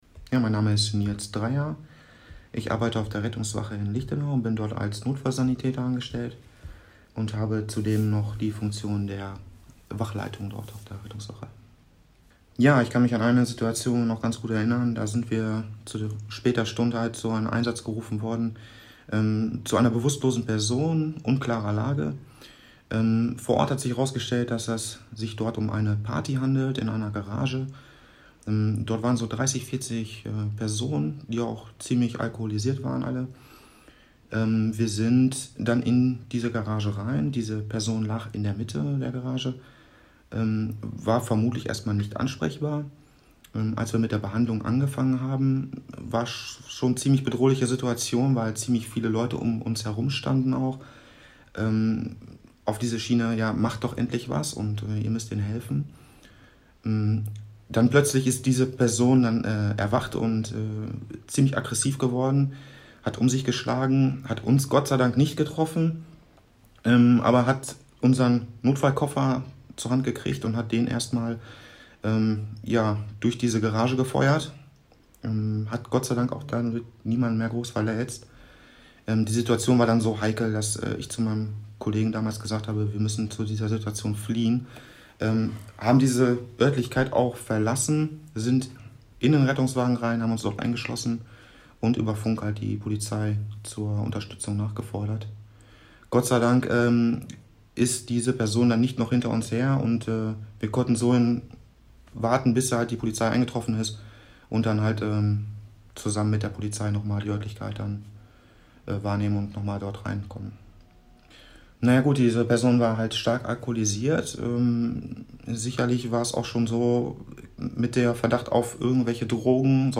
Wir haben Interviews mit den Betroffenen geführt.